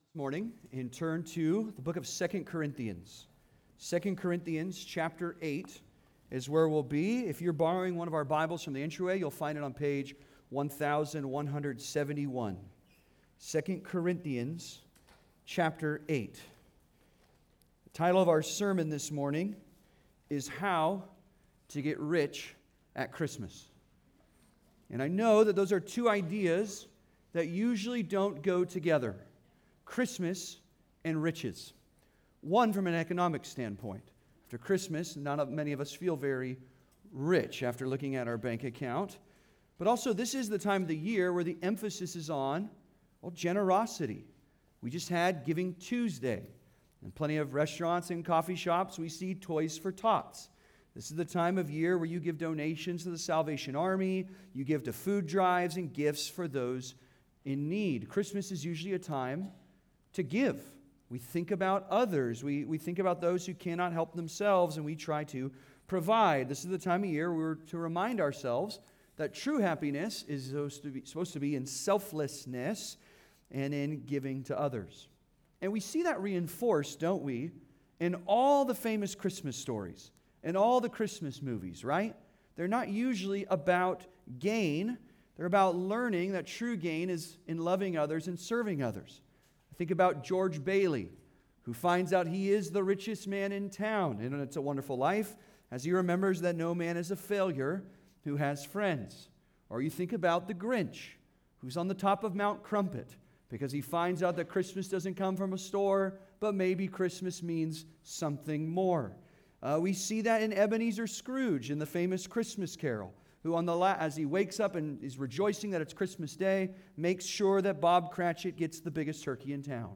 How to Get Rich at Christmas (Sermon) - Compass Bible Church Long Beach